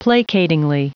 Prononciation du mot placatingly en anglais (fichier audio)
Prononciation du mot : placatingly